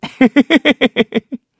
Kibera-Vox_Happy2_kr.wav